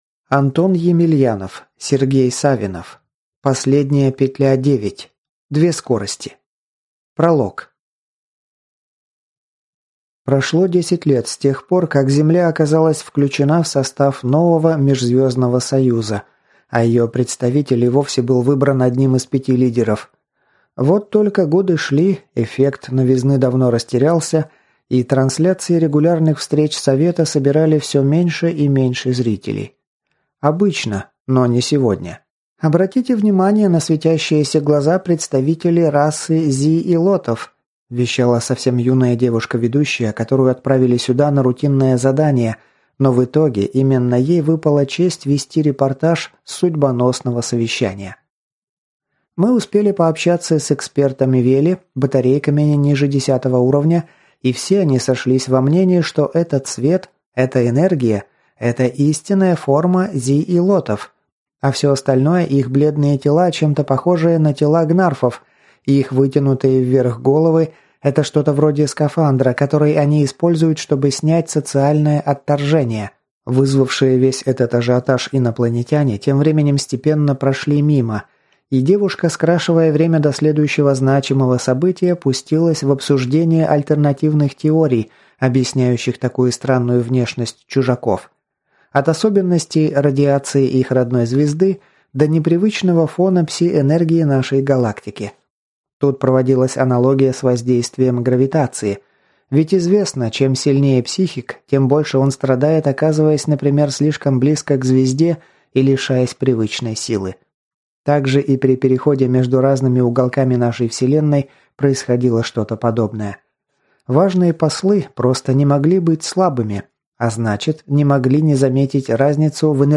Аудиокнига Последняя петля. Книга 9. Две скорости | Библиотека аудиокниг